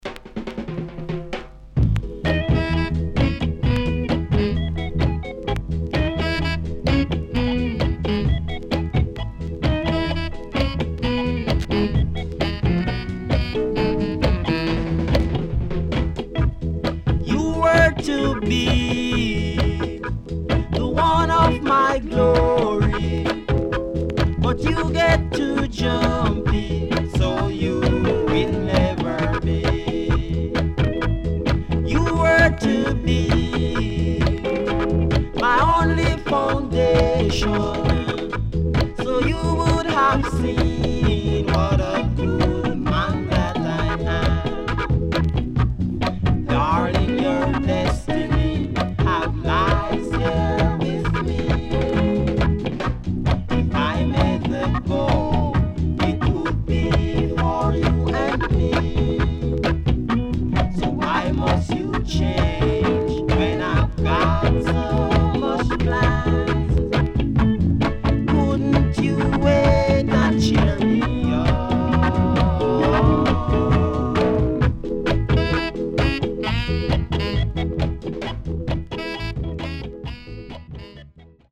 SIDE A:少しノイズ入りますが良好です。
SIDE B:少しノイズ入りますが良好です。